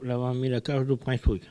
Mémoires et Patrimoines vivants - RaddO est une base de données d'archives iconographiques et sonores.
collecte de locutions vernaculaires
Catégorie Locution